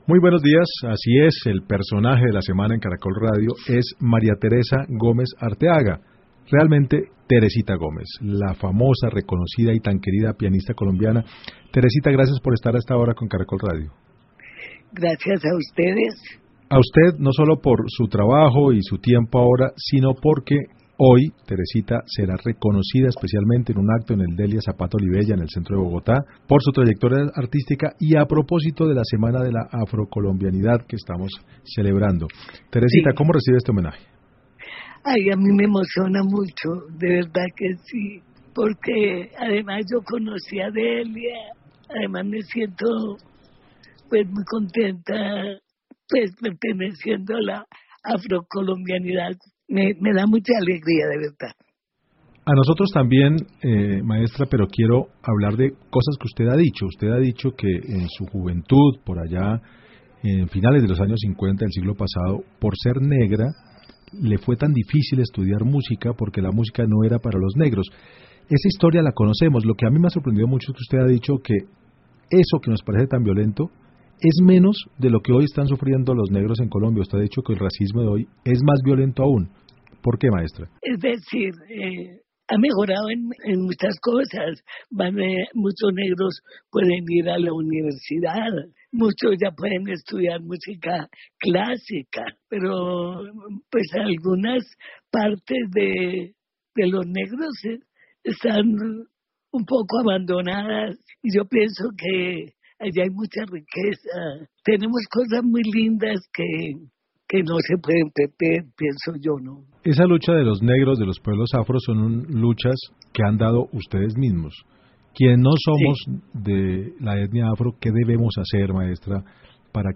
En Caracol Radio, la pianista destacó la importancia de la educación y de mejorar la calidad de vida para las comunidades afrocolombianas.